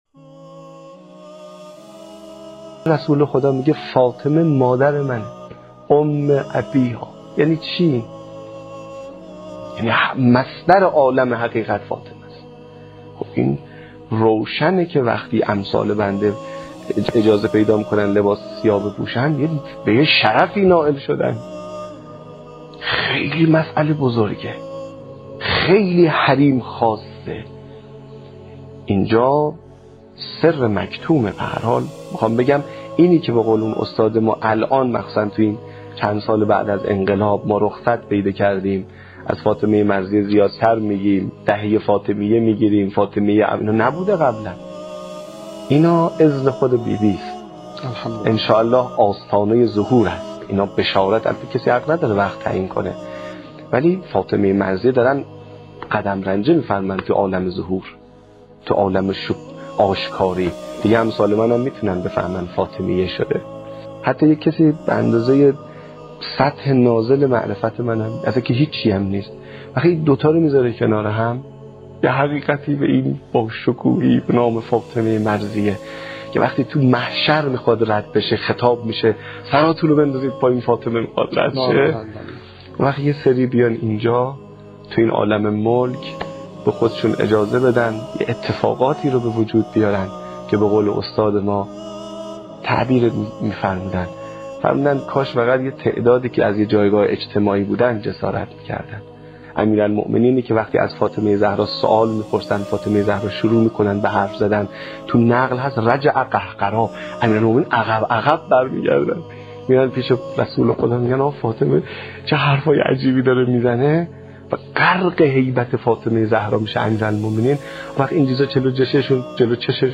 برچسب ها: دهه فاطمیه ، حضرت زهرا(س) ، مصدر عالم حقیقت ، حضرت فاطمه (س)